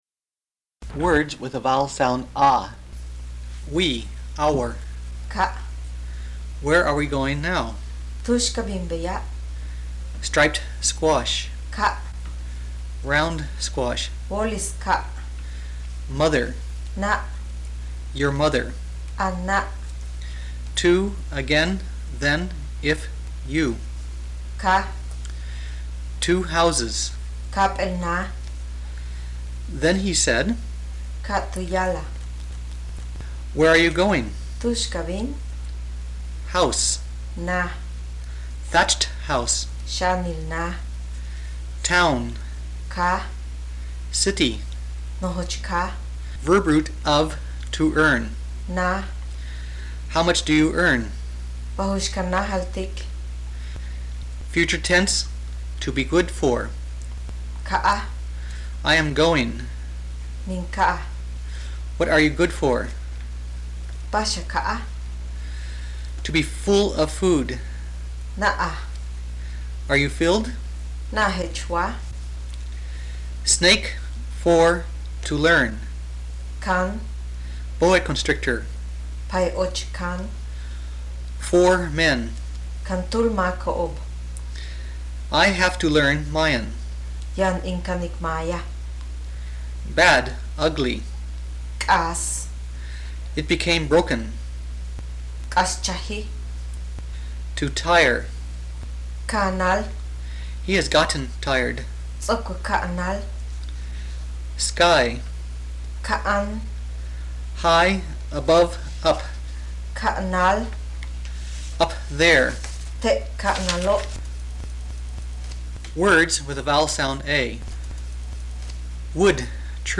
Pronunciation.mp3